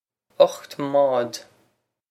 Pronunciation for how to say
ukht mawd
This is an approximate phonetic pronunciation of the phrase.